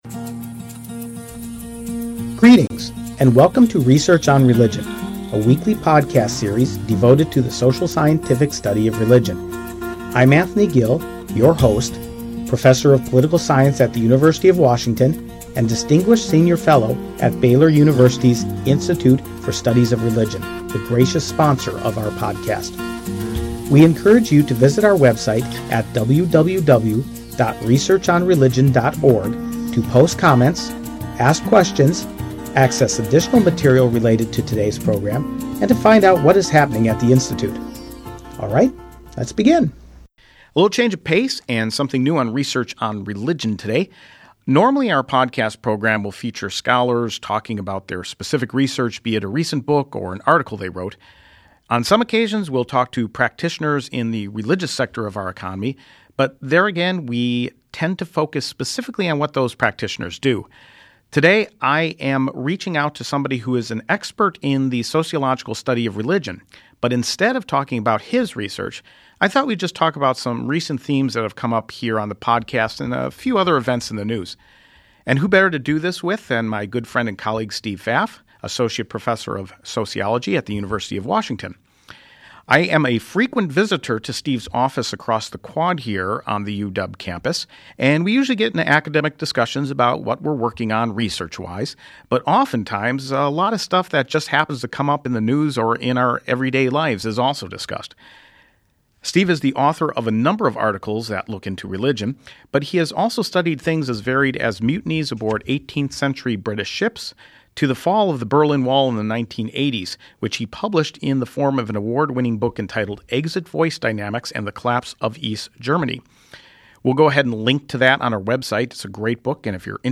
an open-ended discussion